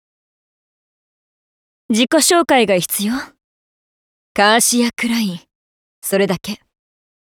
Vo_girl015_gacha001_042.wav